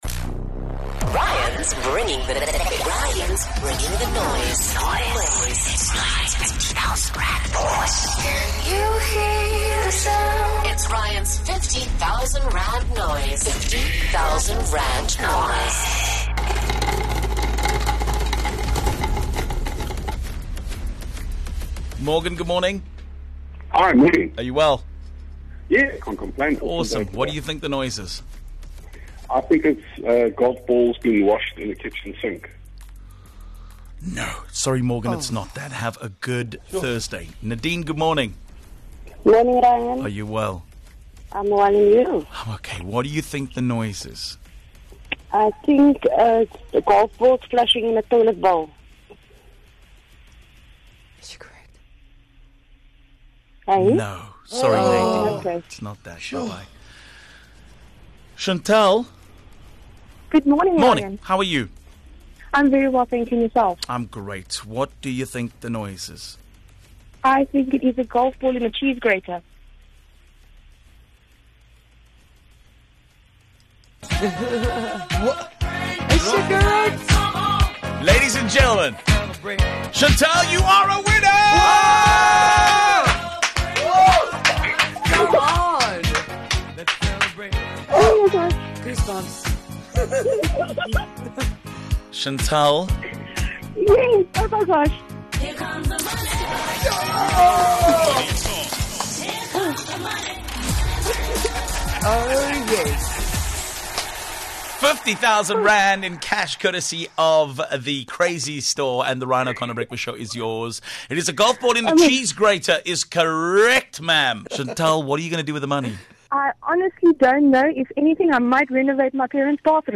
She correctly guessed that it is a golf ball in a cheese grater.